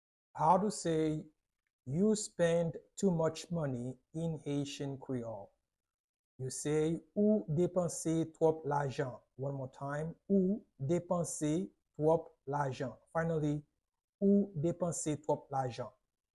“Ou depanse twòp lajan” Pronunciation in Haitian Creole by a native Haitian can be heard in the audio here or in the video below: